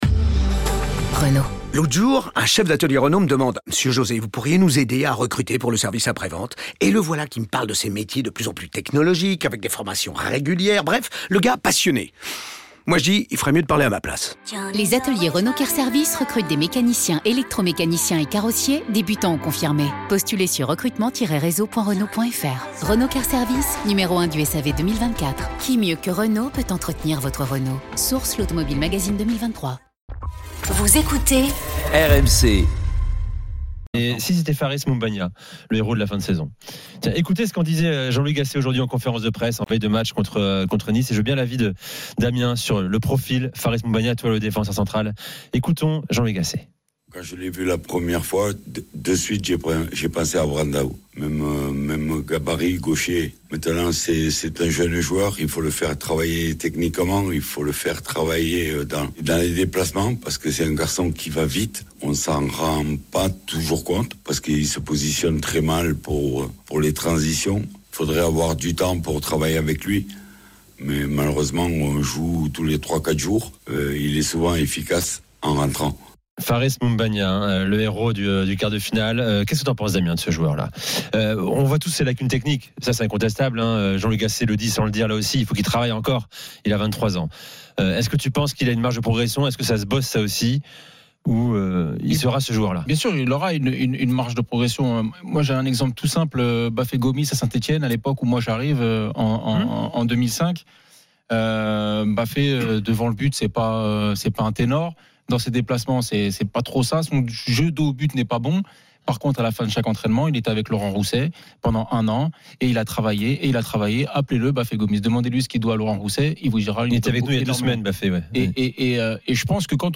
Les rencontres se prolongent tous les soirs avec Gilbert Brisbois et Nicolas Jamain avec les réactions des joueurs et entraîneurs, les conférences de presse d’après-match et les débats animés entre supporters, experts de l’After et auditeurs. RMC est une radio généraliste, essentiellement axée sur l'actualité et sur l'interactivité avec les auditeurs, dans un format 100% parlé, inédit en France.